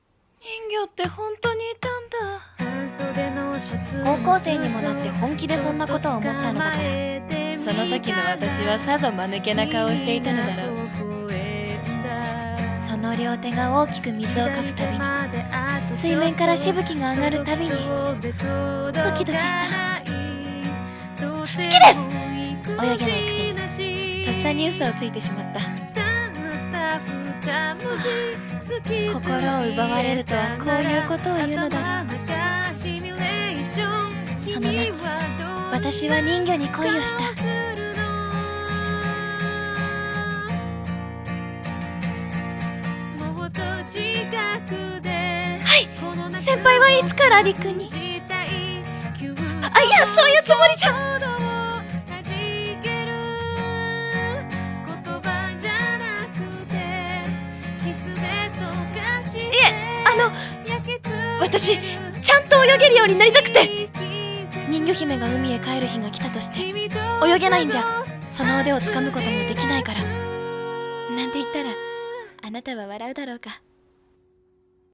【声劇台本】人魚